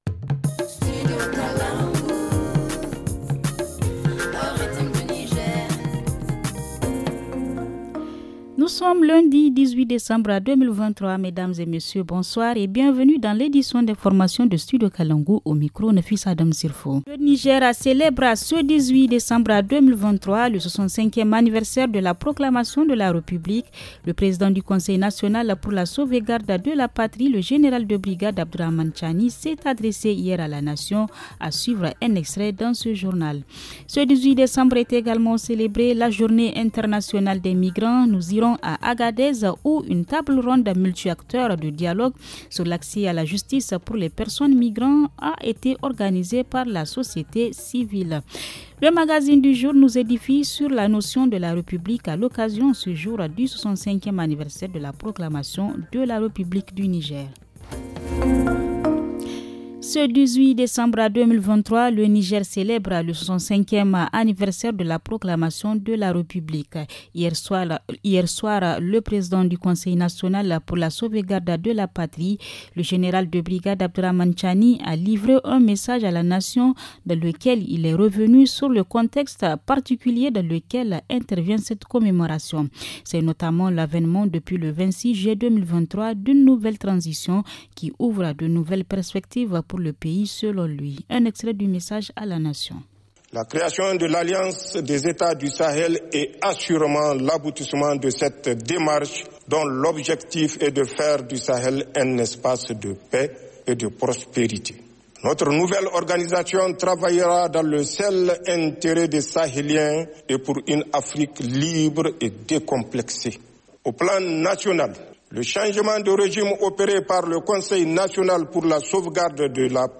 –Gouvernance : Extrait message à la nation du président du CNSP à l’occasion de la fête de la République ;